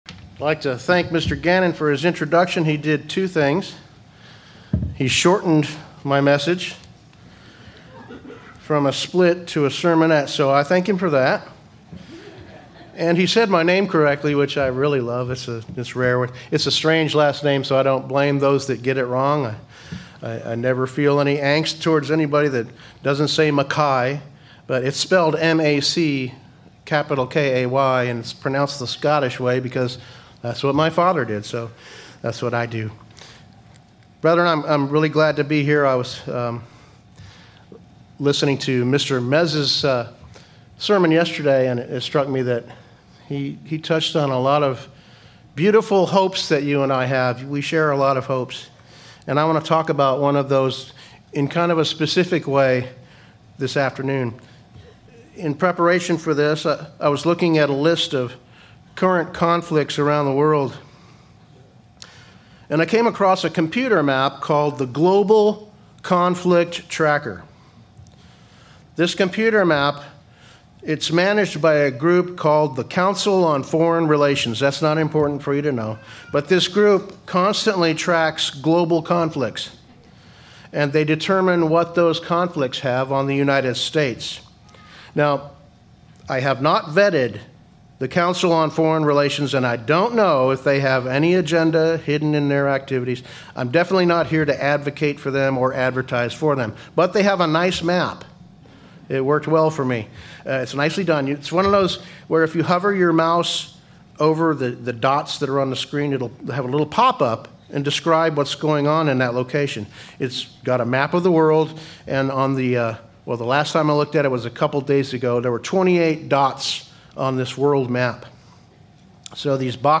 Given in Branson, Missouri